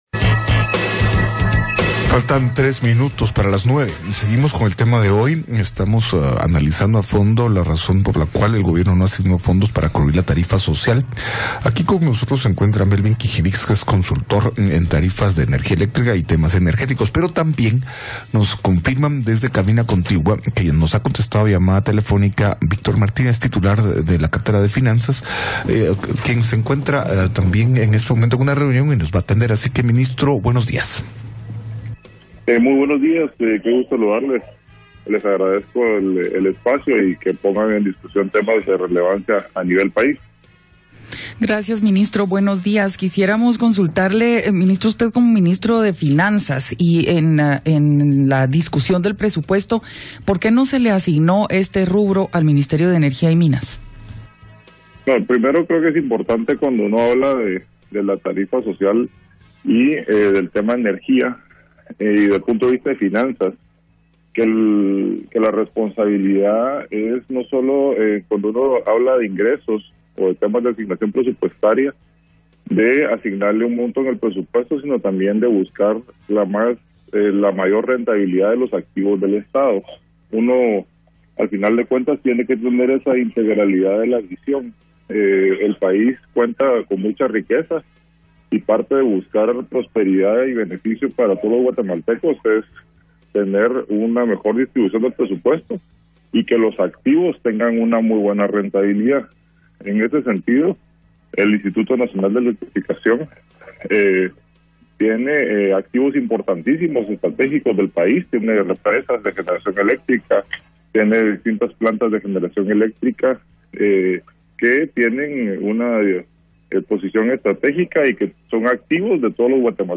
PATRULLAJE INFORMATIVO / EMISORAS UNIDAS; Entrevista